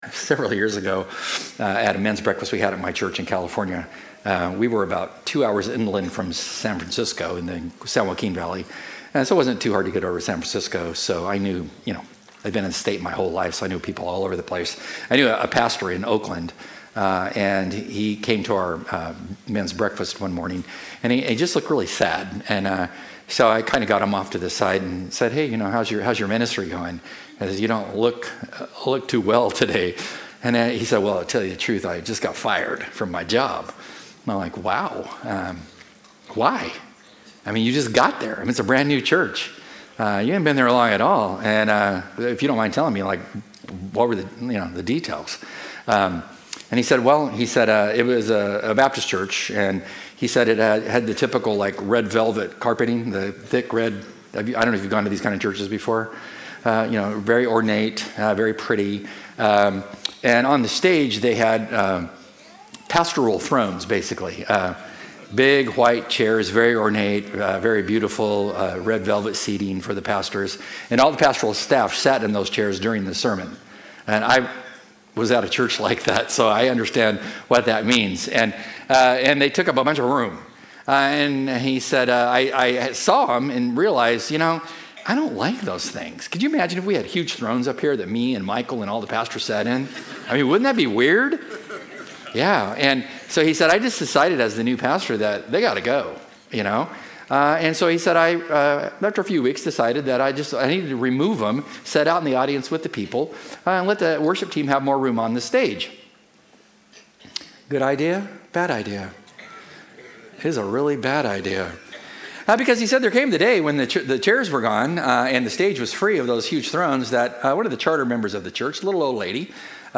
As we conclude our "One Another" sermon series we take a look at what it means to come along side others during tough times and help carry their load.